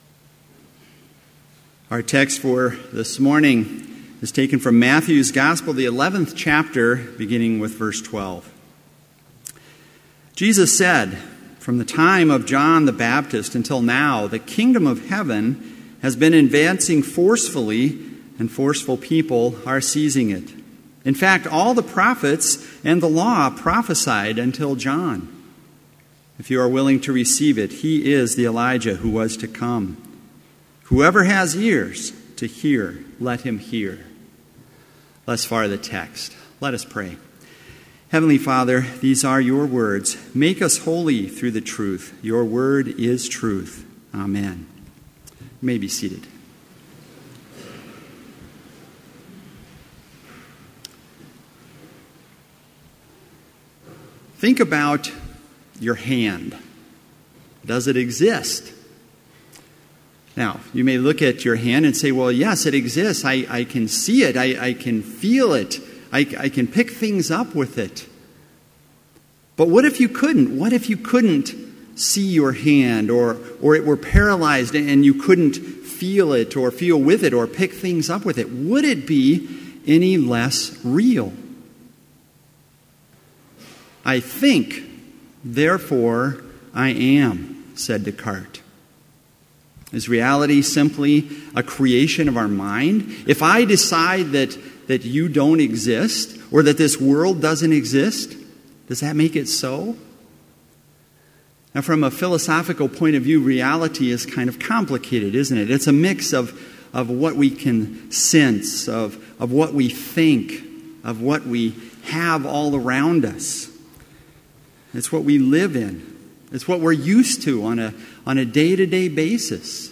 Complete service audio for Chapel - October 26, 2017